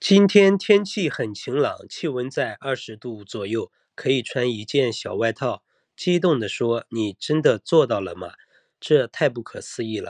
没入型AIヒーリングストーリーテリングボイス
マインドフルネス、スリープストーリー、感情的な癒しのコンテンツのために設計された、穏やかで自然な響きのAI音声を体験してください。
癒しのナレーション
Noiz.aiのヒーリングストーリーテリングボイスは、リスナーを内省とリラクゼーションの瞬間に導く、優しく共感的なトーンを提供します。
治療的なコンテンツに合わせた自然な抑揚と癒しのイントネーション。